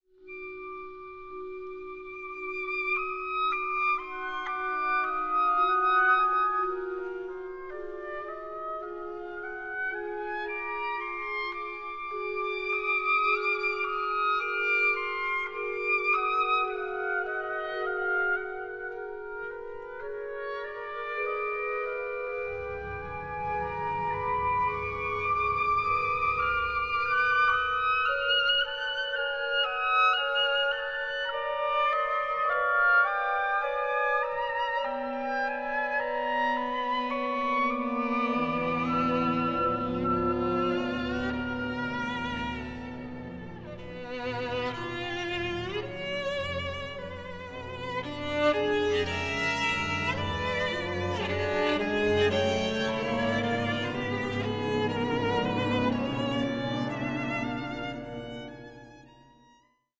for Oboe, Violin and Orchestra
Andante 9:30